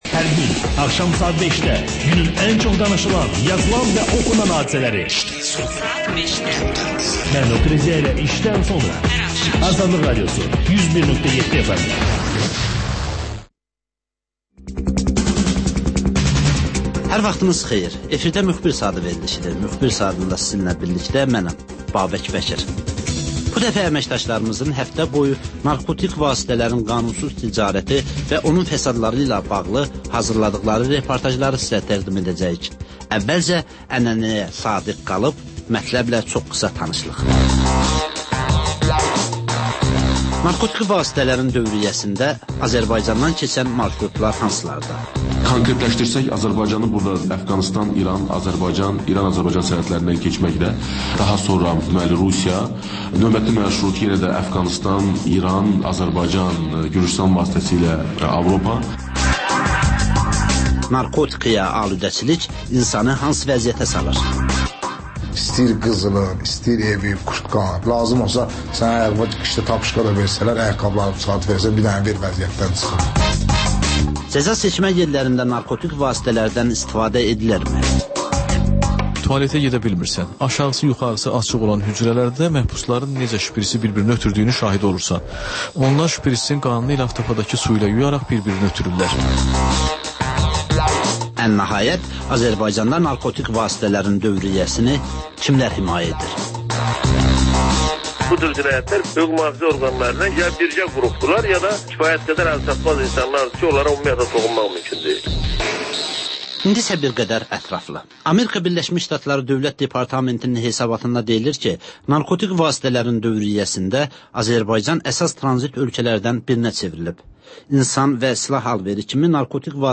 Müxbirlərimizin həftə ərzində hazırladıqları ən yaxşı reportajlardan ibarət paket